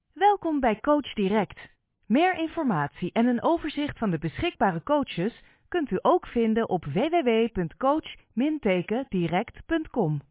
Sou uma locutora profissional holandesa com uma voz clara, calorosa e fresca.